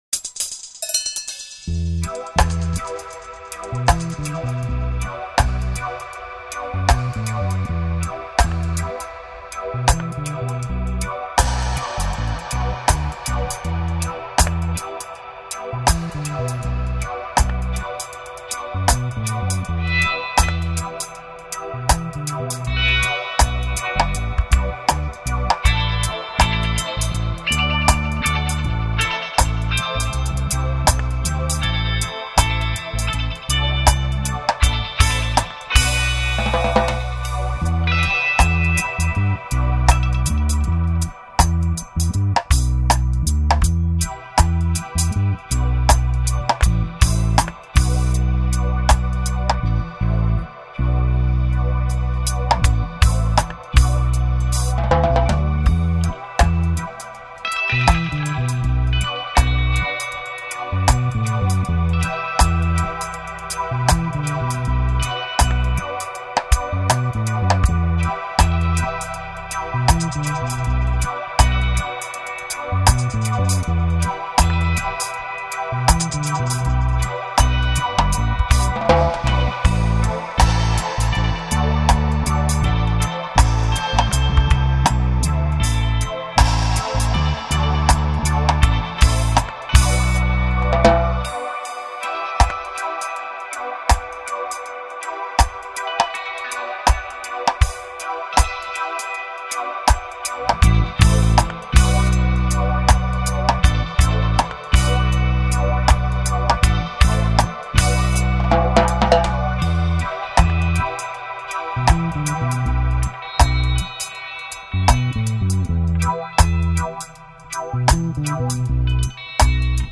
Recorded on the north-side OF TOWN